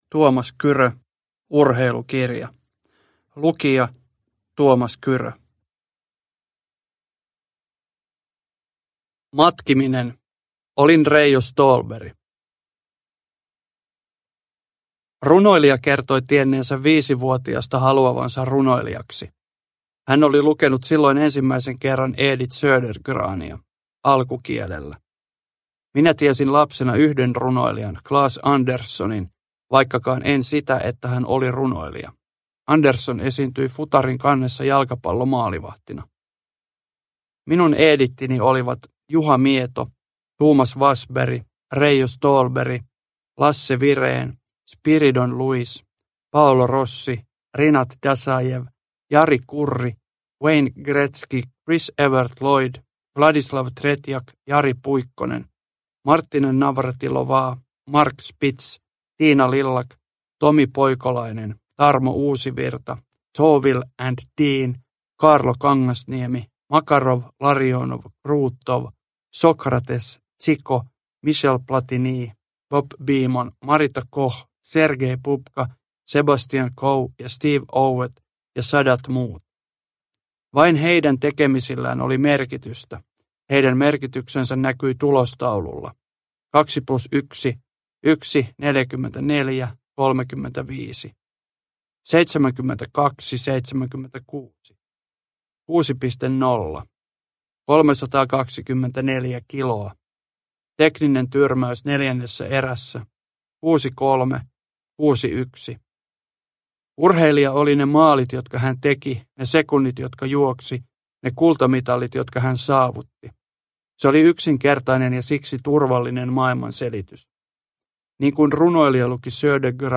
Urheilukirja – Ljudbok
Uppläsare: Tuomas Kyrö